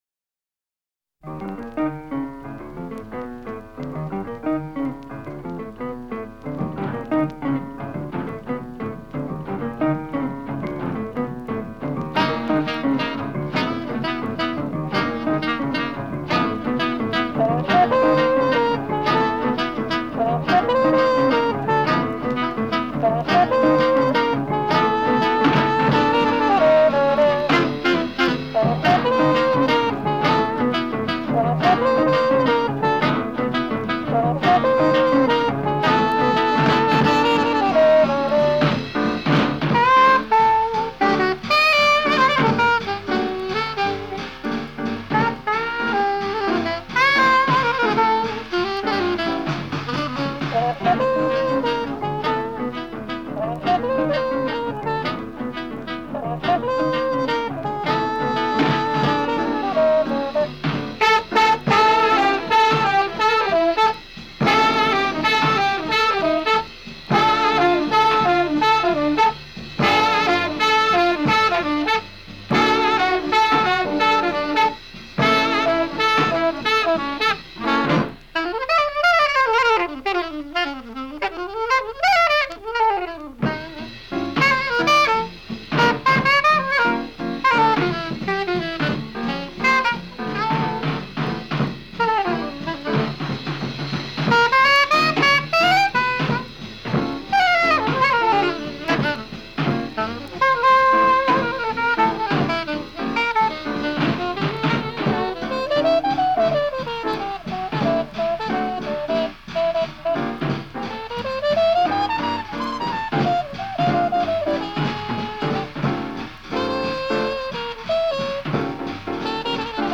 la verve appassionata dei breaks